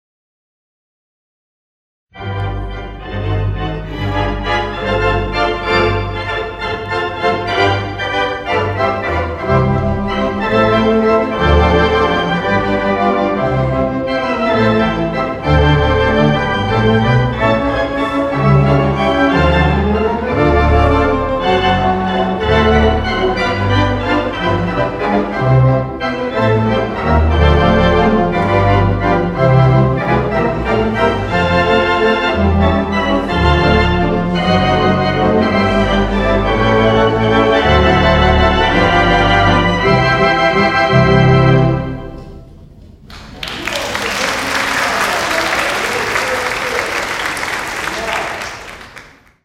Mighty 2/5 Wicks Theatre Pipe Organ
and in front of an audience of over 200 attendees